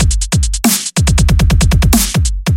描述：杜比斯特拍子在140。在Fl Studio制作
标签： 140 bpm Dubstep Loops Drum Loops 443.01 KB wav Key : Unknown
声道立体声